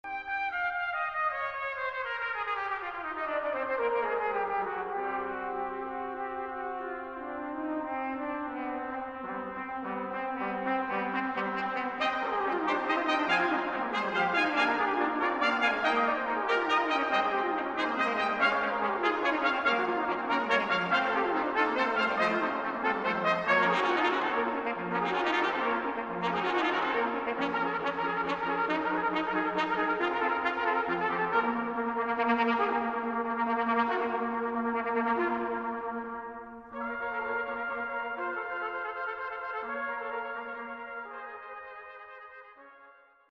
Baroque